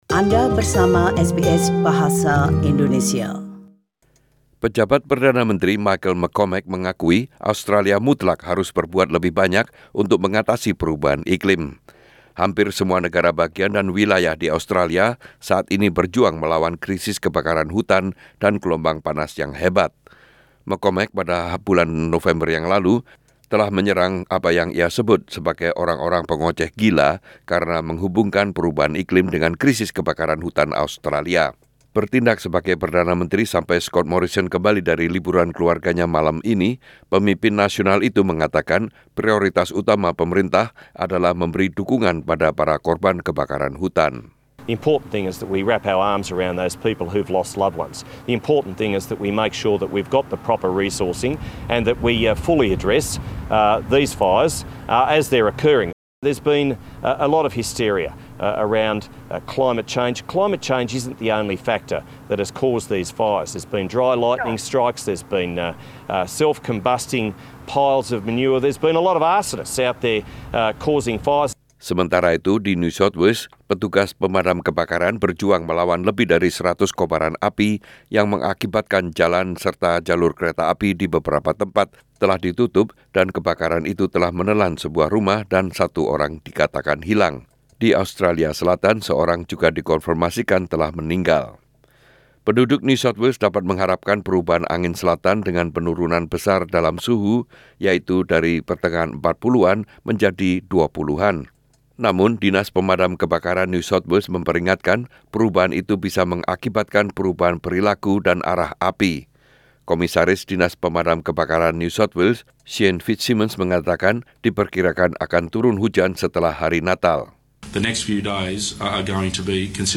Warta Berita Radio SBS dalam Bahasa Indonesia - 22 Desember 2019